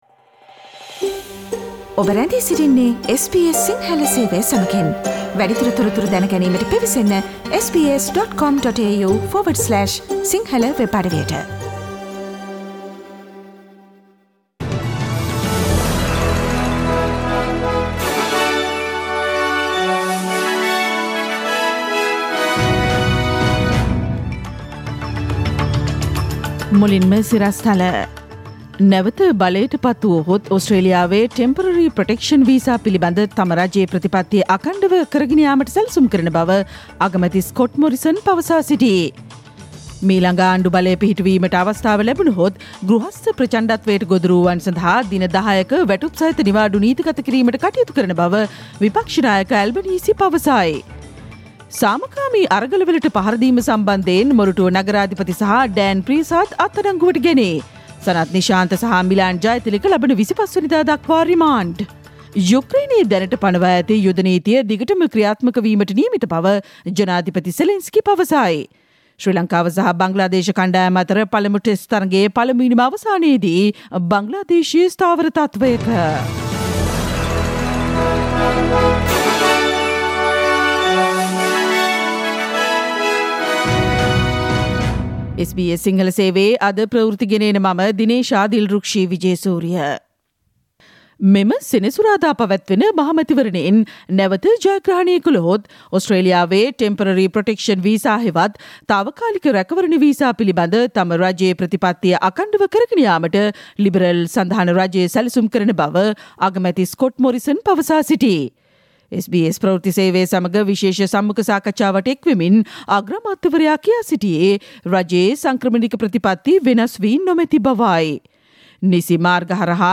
Click on the speaker icon on the image above to listen to the SBS Sinhala Radio news bulletin on Thursday 19 May 2022.